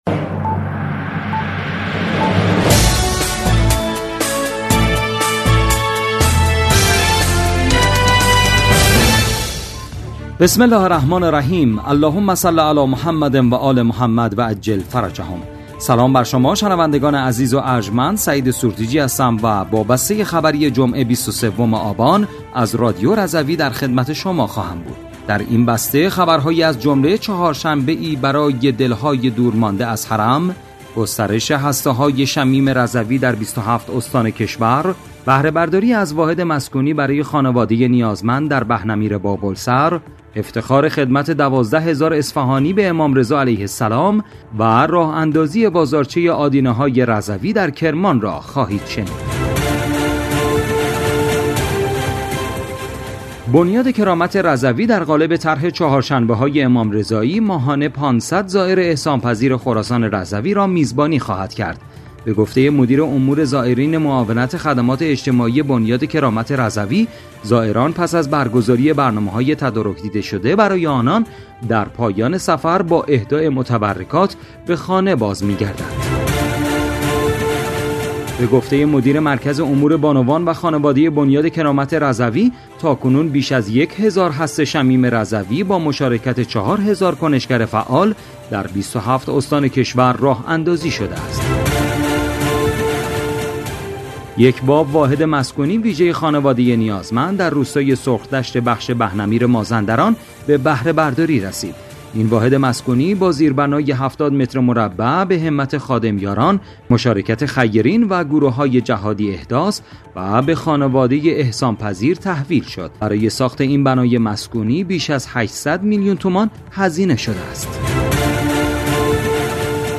بسته خبری ۲۳ آبان ۱۴۰۴ رادیو رضوی؛